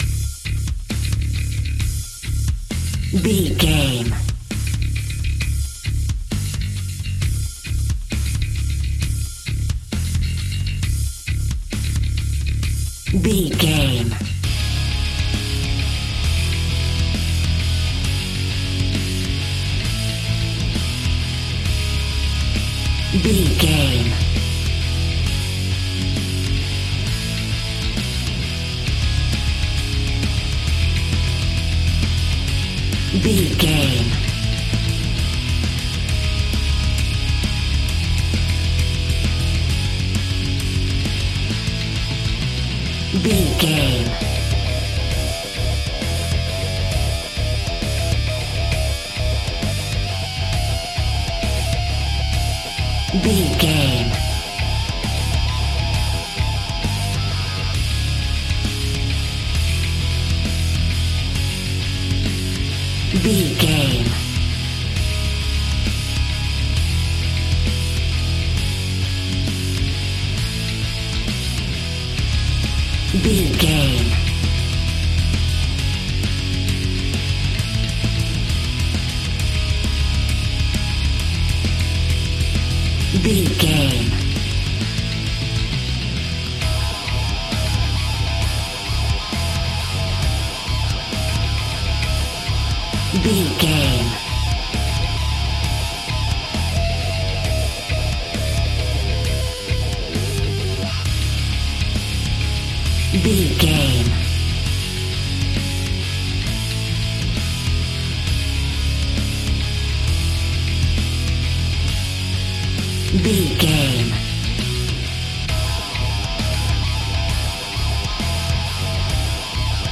Epic / Action
Aeolian/Minor
C♯
Slow